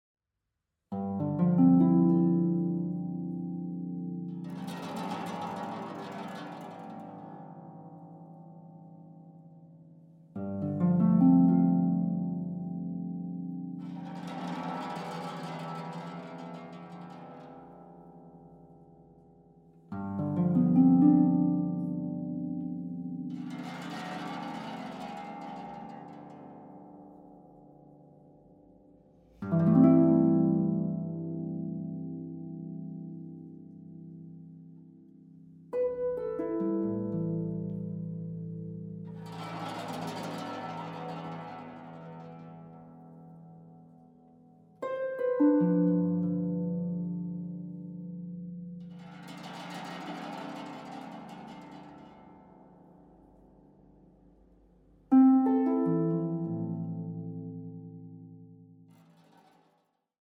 Contemporary Music for Harp
Harp